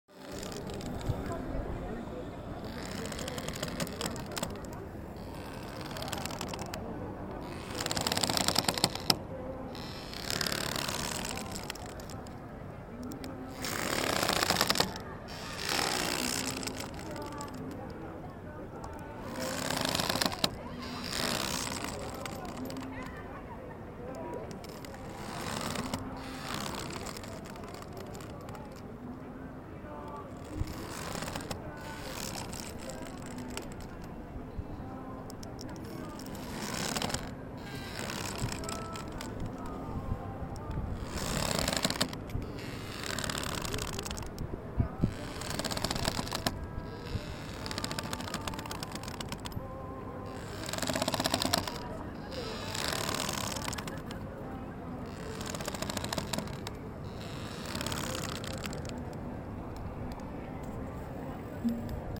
Download Rope sound effect for free.
Rope